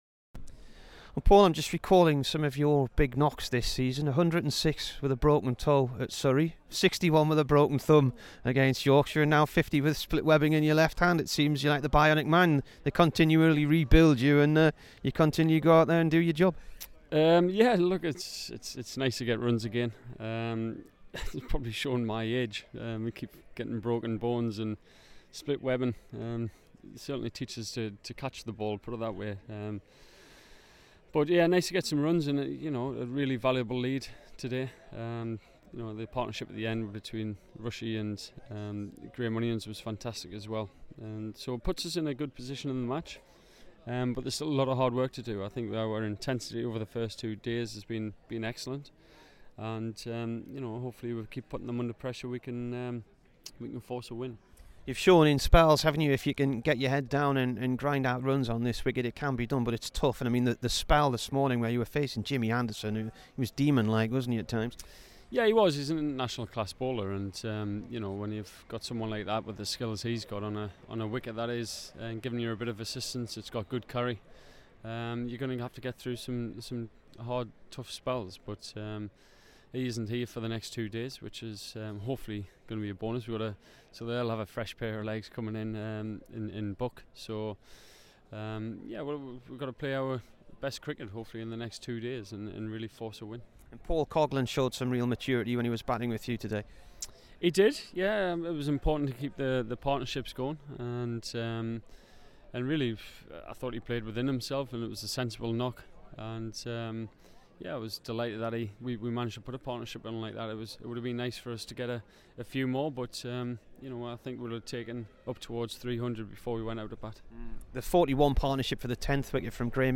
Paul Collingwood int